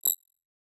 Holographic UI Sounds 55.wav